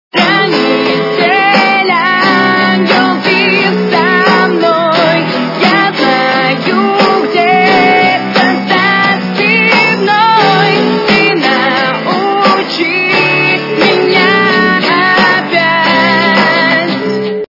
- русская эстрада
качество понижено и присутствуют гудки